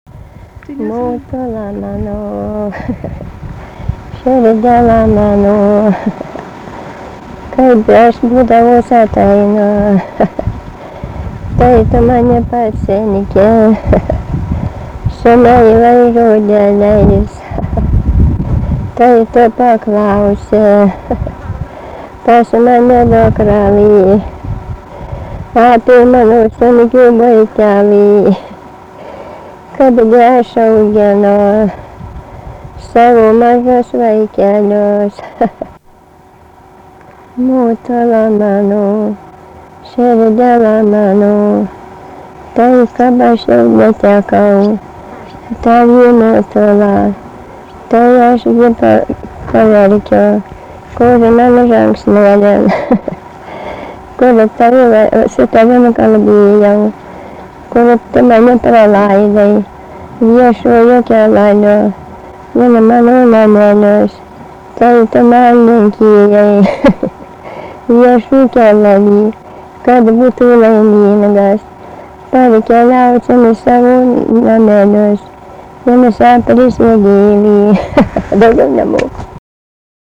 Dalykas, tema daina
Erdvinė aprėptis Pauosupė
Atlikimo pubūdis vokalinis